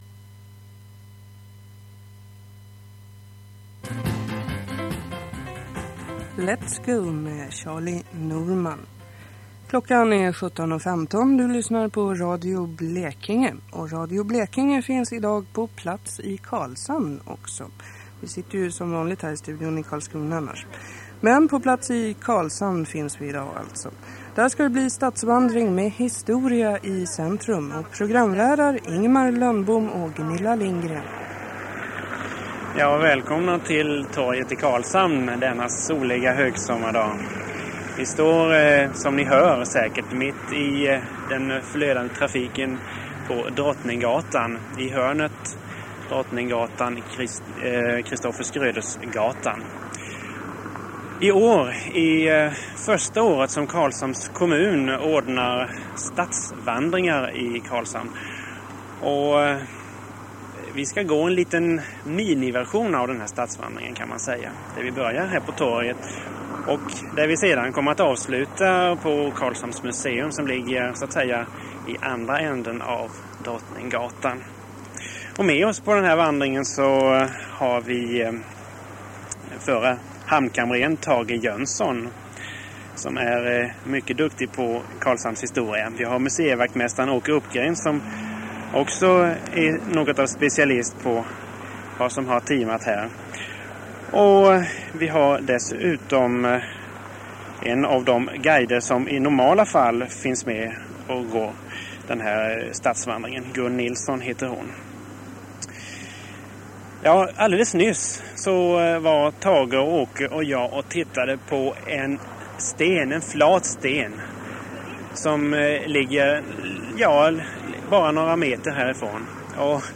Stadsvandring i Karlshamn 1983